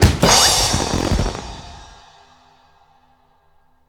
confetti_01.ogg